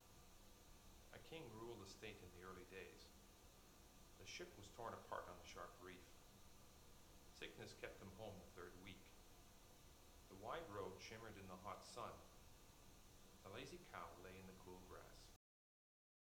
How much the headphones attenuate the voice of a person talking to you when background noise is present.
Male Voice 1
male-voice-1-sample.wav